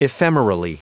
Prononciation du mot ephemerally en anglais (fichier audio)